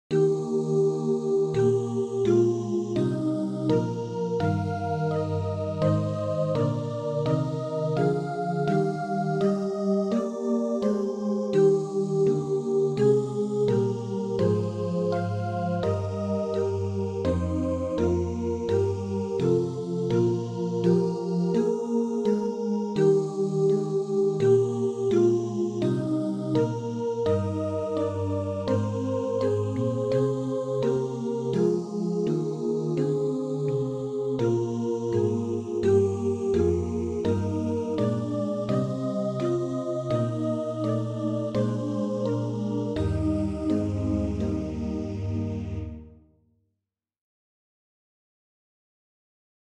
SATB , Instrument Ensemble , SATB quartet , String Quartet